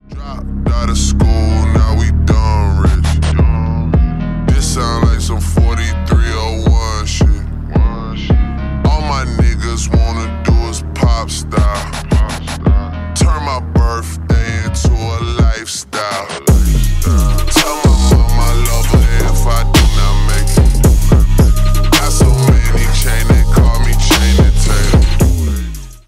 Ремикс # Рэп и Хип Хоп
клубные